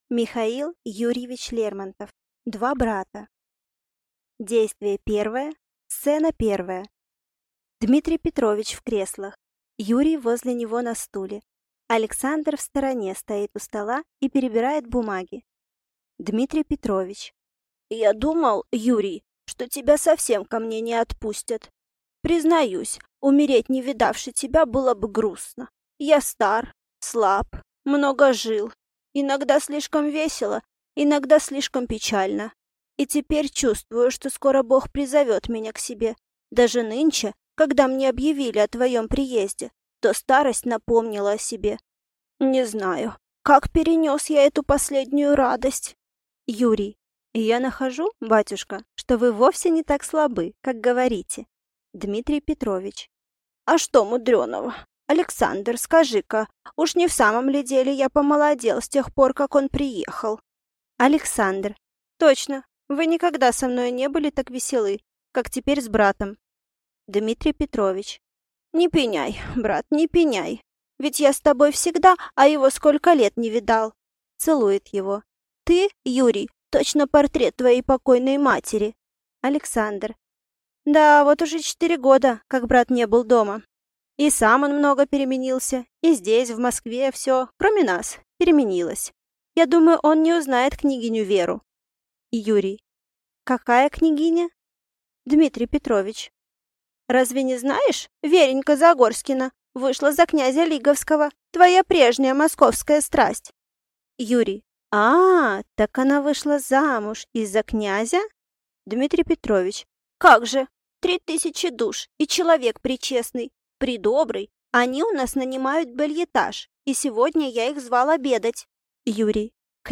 Аудиокнига Два брата | Библиотека аудиокниг